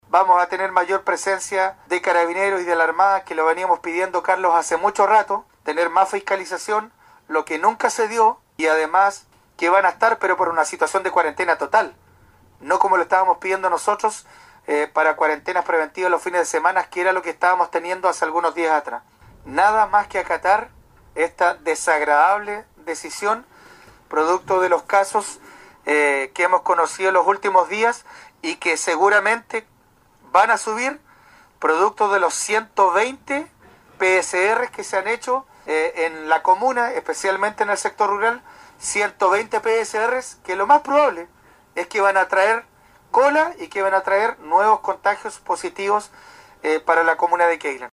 El alcalde de Quéilen expresó su preocupación por que existía, hasta la emisión de esta declaración en las redes sociales del municipio, un total de 120 PCR realizados y cuyos resultados se iban a conocer dentro de estas horas, por lo que se espera que las cifras sigan subiendo.
20-ALCALDE-QUEILEN-2.mp3